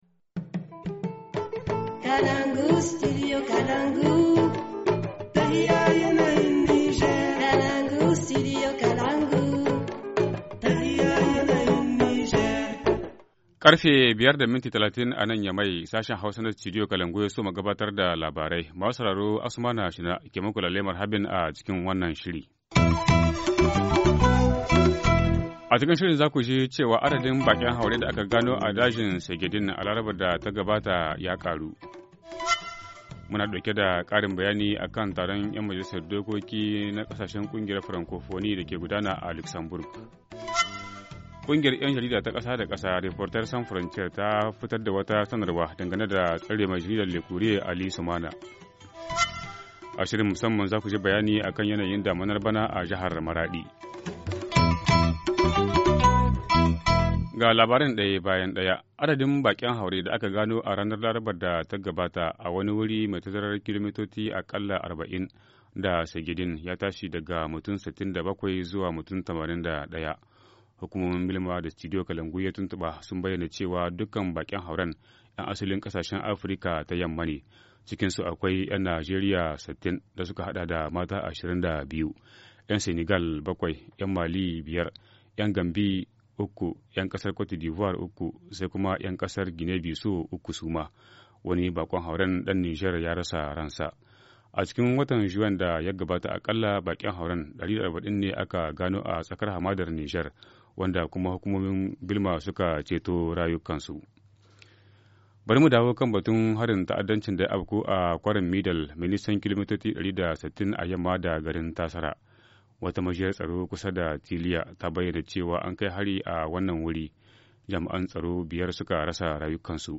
Journal du 7 juillet 2017 - Studio Kalangou - Au rythme du Niger